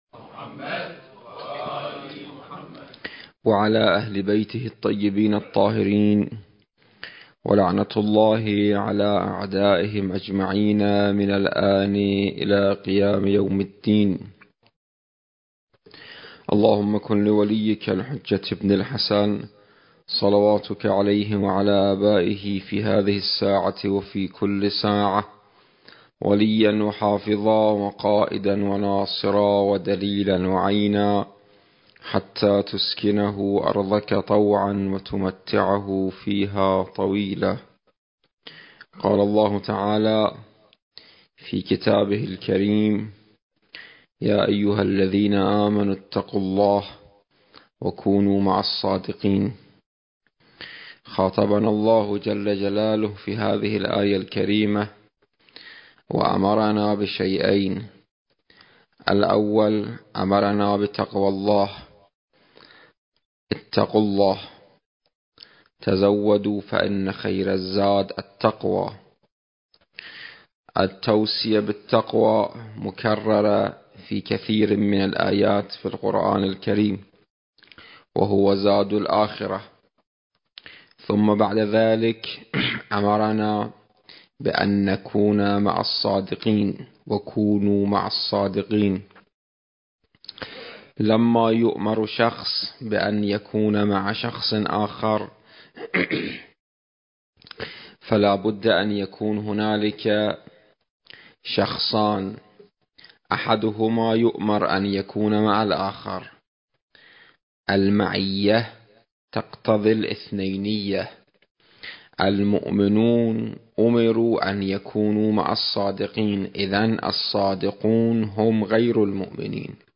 المكان: جامع وحسينية أهل البيت (عليهم السلام) / بغداد